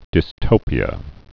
dystopia (dĭs-tō'pē-ə) n.